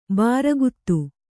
♪ bāraguttu